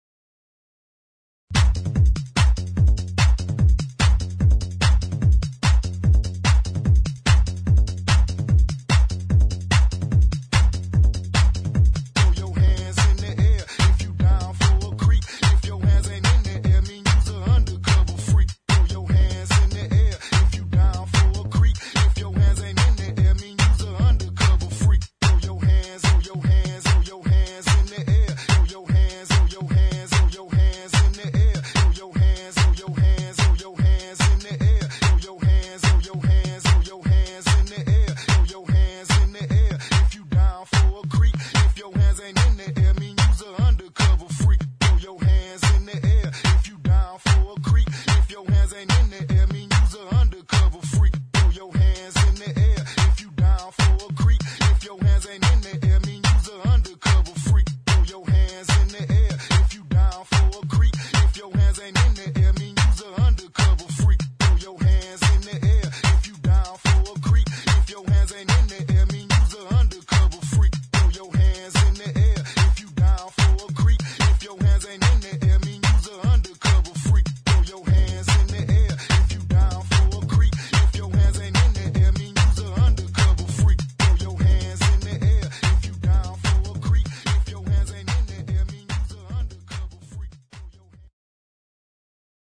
[ GHETTO | ELECTRO ]
エレクトロでファンキーなゲットー・テック・ベース！